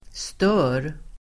Uttal: [stö:r]